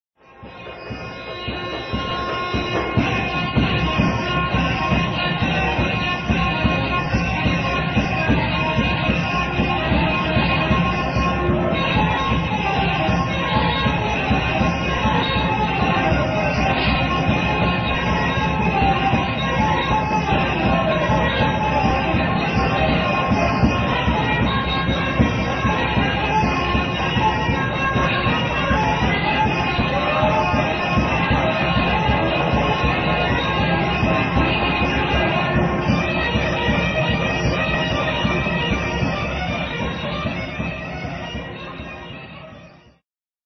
"Jumping" Tik sometimes called Tik Serviko "Serbian Tik"